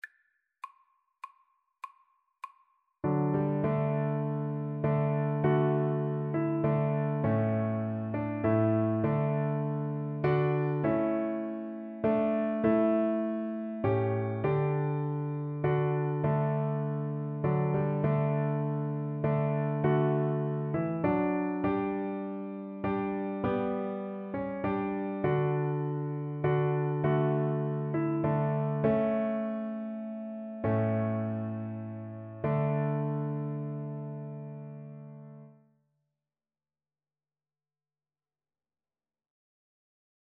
6/4 (View more 6/4 Music)